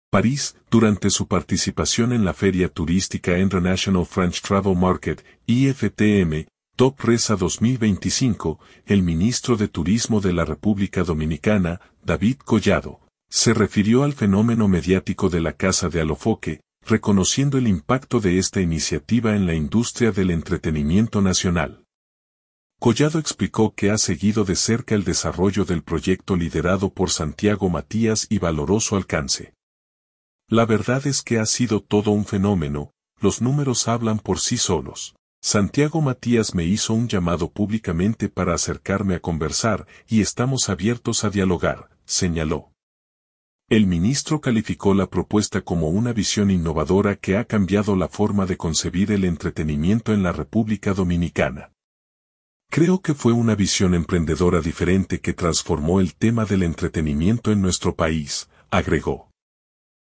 París.- Durante su participación en la feria turística International French Travel Market (IFTM) – Top Resa 2025, el ministro de Turismo de la República Dominicana, David Collado, se refirió al fenómeno mediático de la Casa de Alofoke, reconociendo el impacto de esta iniciativa en la industria del entretenimiento nacional.